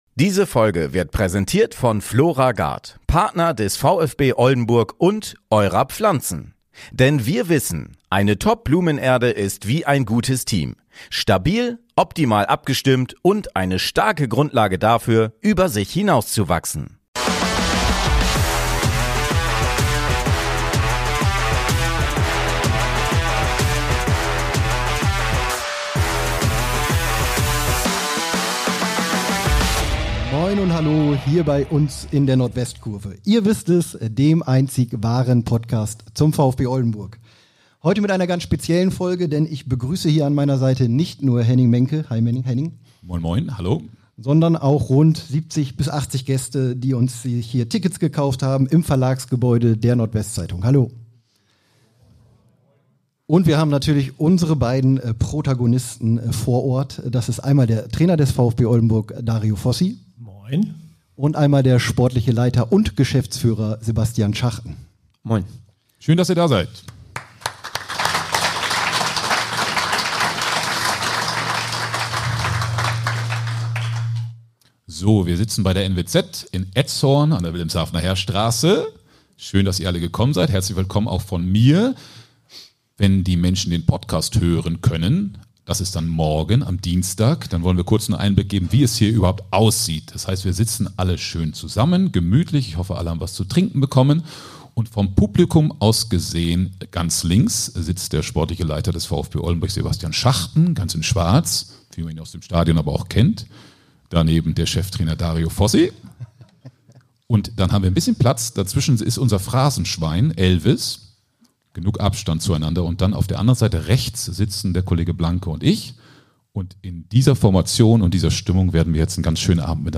Beschreibung vor 1 Woche Es ist eine besondere Ausgabe von Nordwestkurve, dem Fußball-Podcast zum VfB Oldenburg.